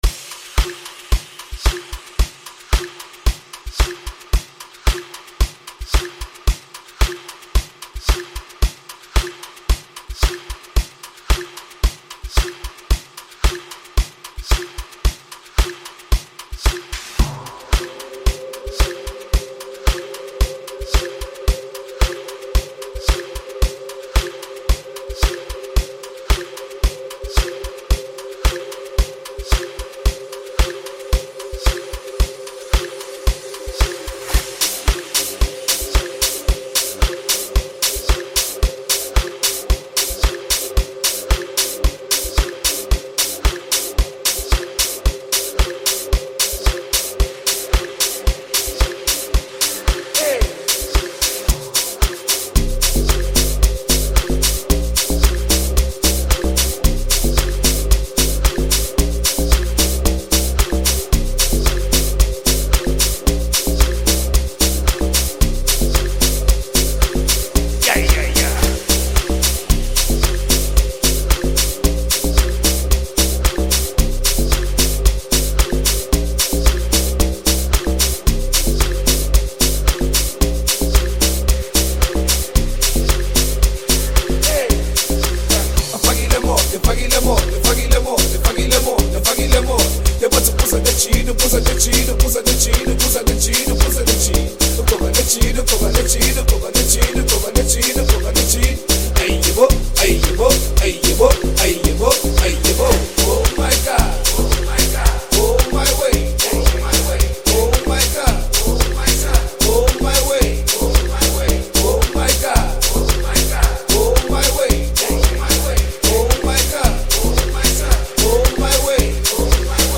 Amapiano genre